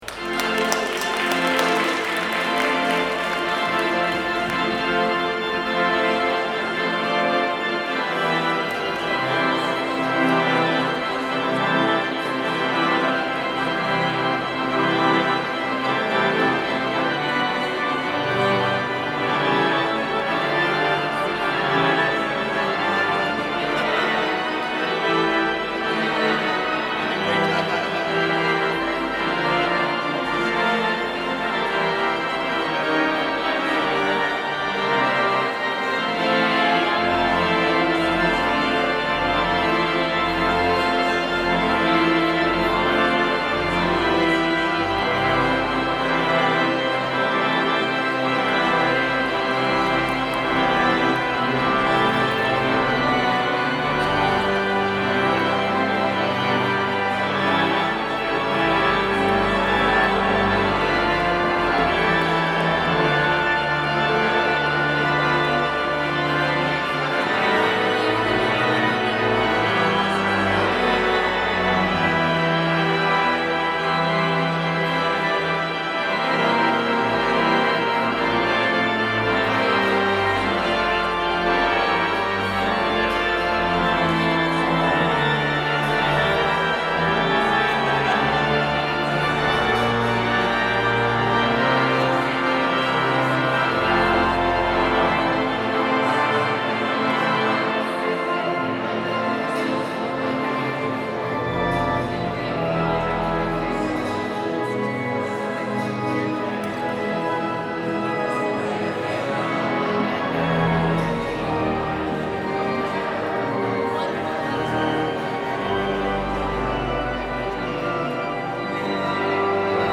Postlude
Lakewood Cluster Choirs of Saint Clement, Saint James and Saint Luke
2023 Easter Vigil
Organ Solo